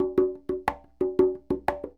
44 Bongo 21.wav